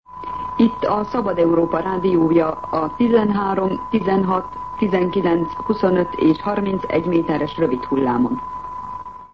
Frekvenciaismertetés